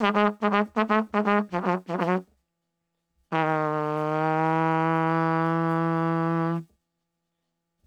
Etrumpet.wav